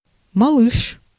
Ääntäminen
IPA: /bɑ̃.bɛ̃/